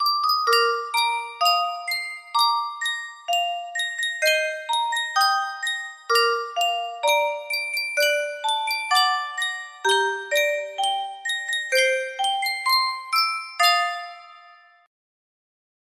Sankyo Music Box - Our Delaware CMA
Full range 60